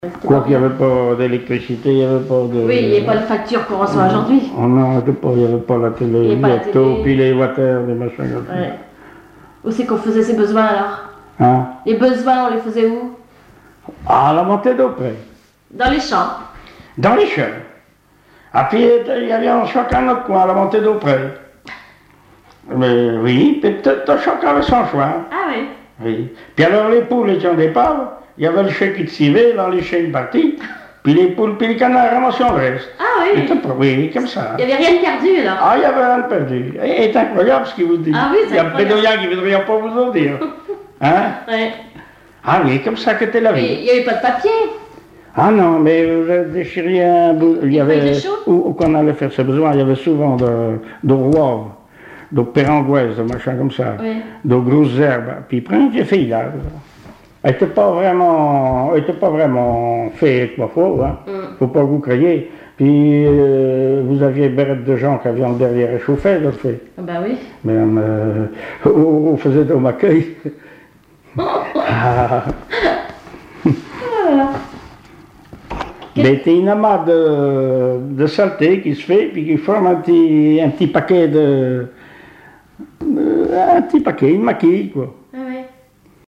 Langue Patois local
Catégorie Témoignage